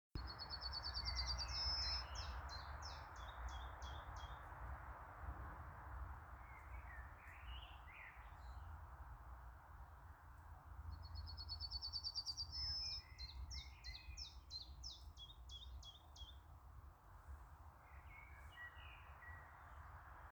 обыкновенная овсянка, Emberiza citrinella
СтатусПоёт
ПримечанияDzirdēta dziedam bērzā, pļavas malā netālu no ezera.